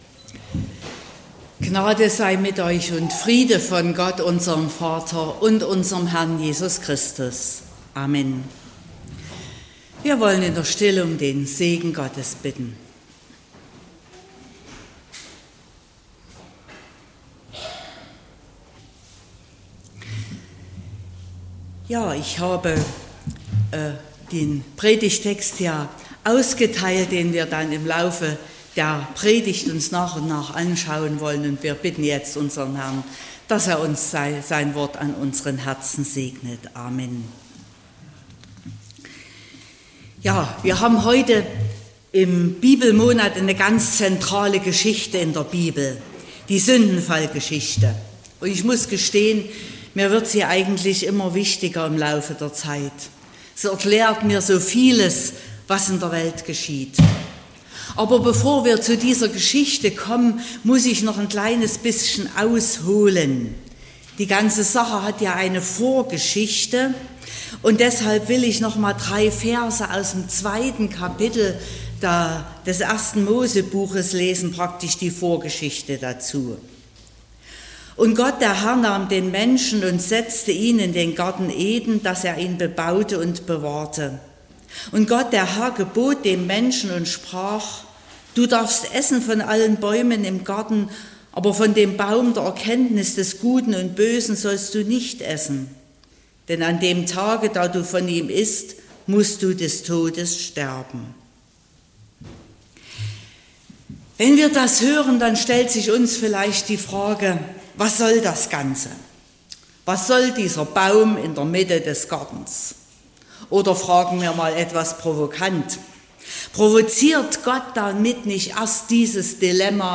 08.09.2024 – Gottesdienst
Predigt und Aufzeichnungen